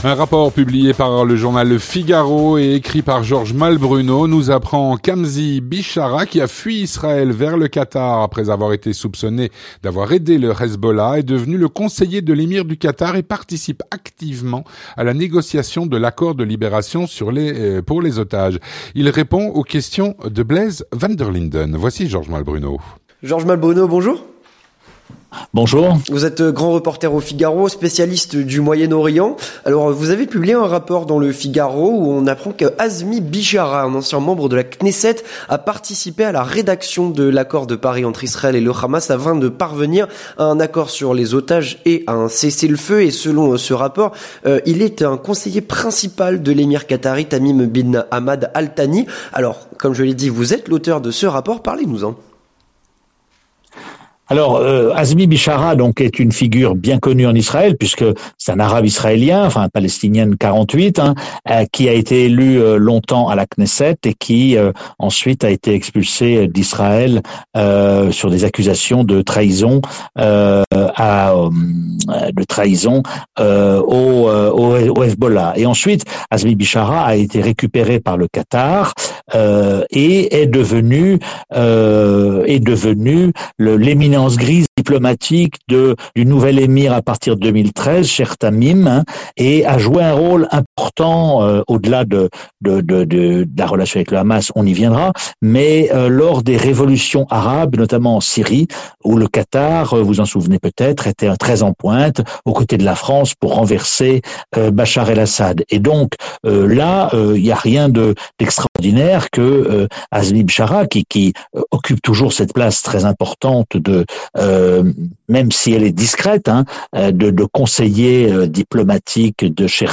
Avec Georges Malbrunot, grand reporter au Figaro, spécialiste du Moyen Orient et auteur de ce rapport.